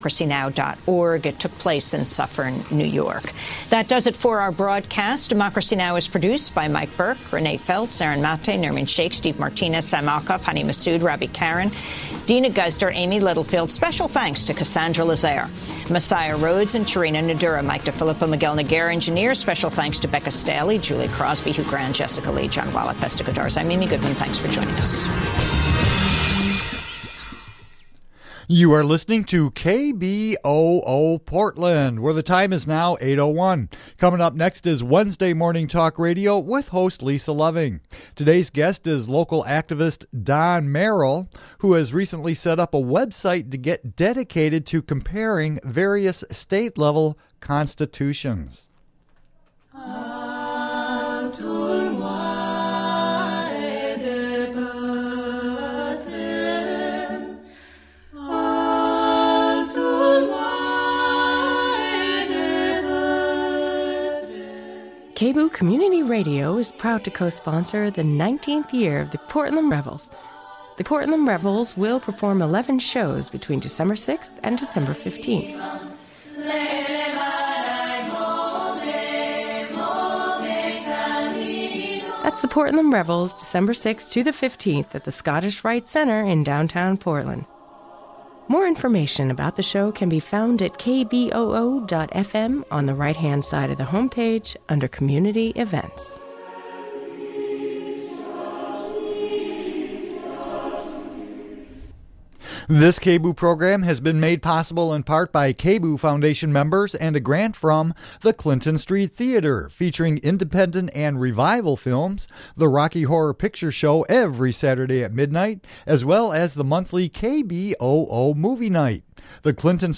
Wednesday Talk Radio